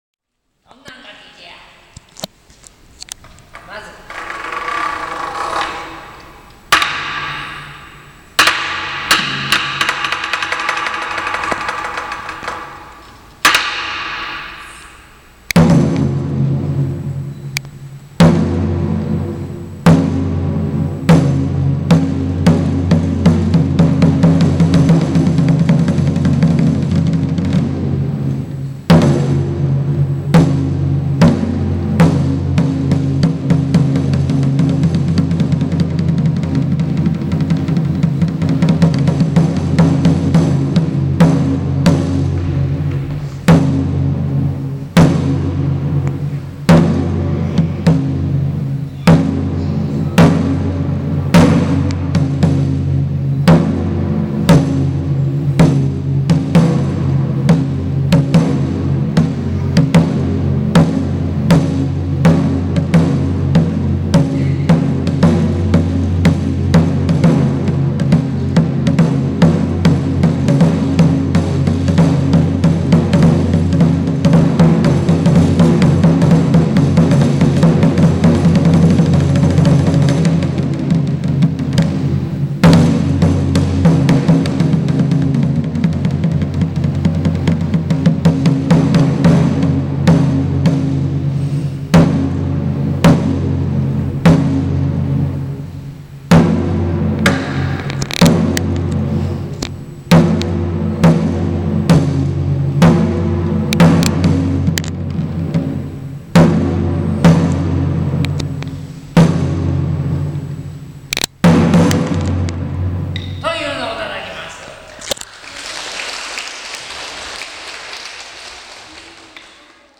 落語がはじまる合図となる
クリックすると，「一番太鼓」の音が聴けます。